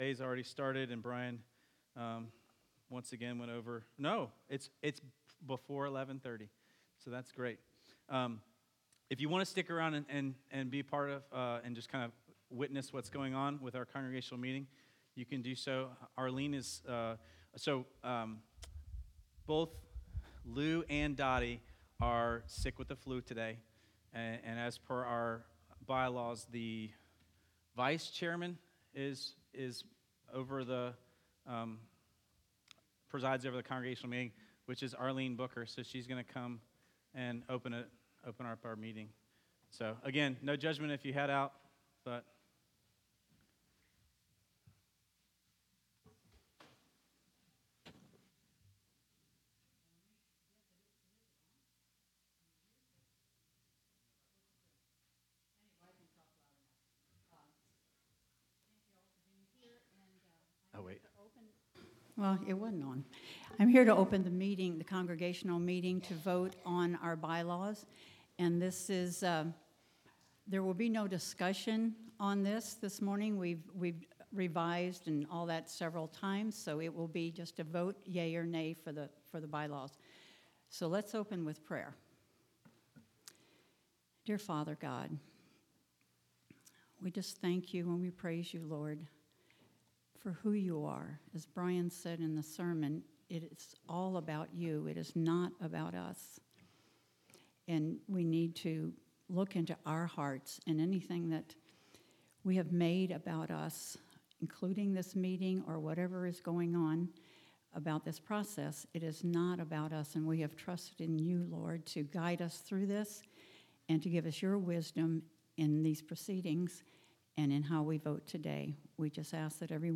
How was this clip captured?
March 3, 2019 Congregational Meeting